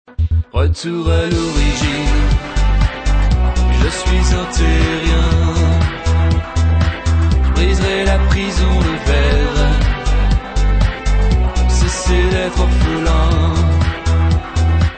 chanson influences rock